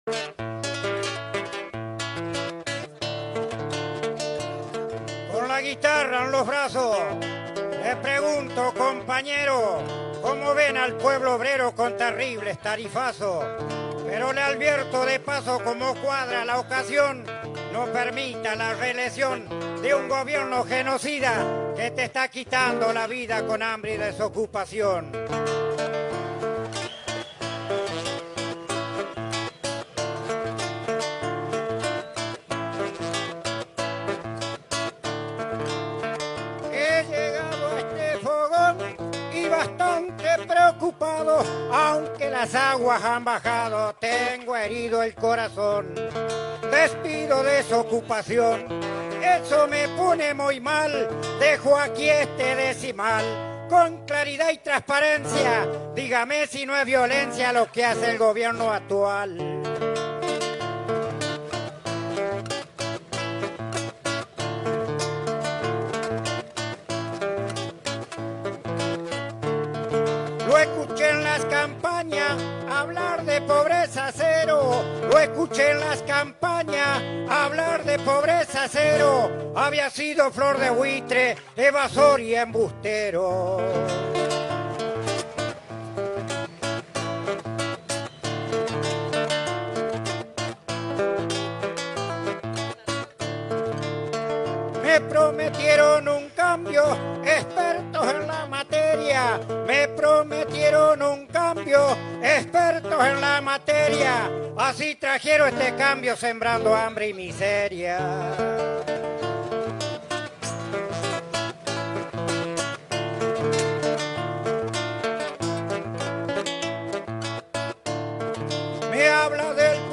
El decidor entrerriano entona su canto al gobierno nacional en un acto realizado el sábado último en Villaguay.
Payador-entrerriano-da-cuenta-del-gobierno-de-Macri.mp3